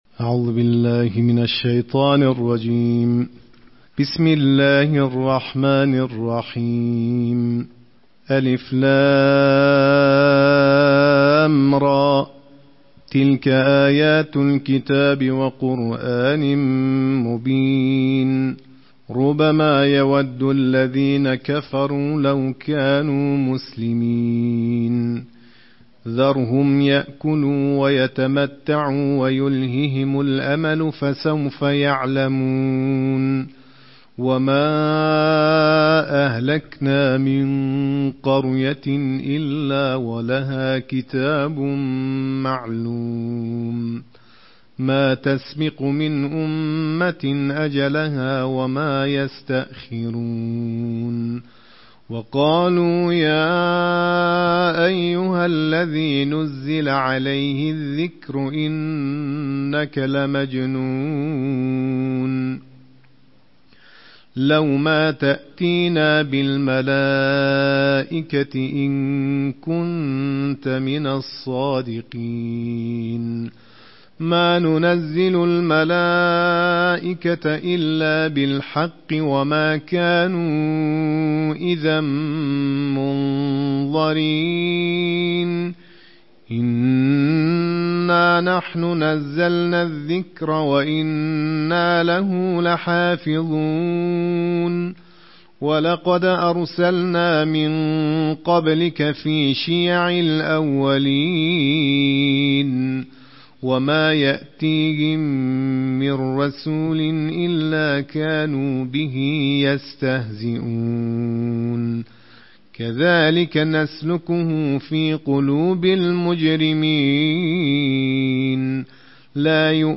अंतरराष्ट्रीय क़ारीयों की आवाज के साथ कुरान के 14वें पारे की तिलावत+ ऑडियो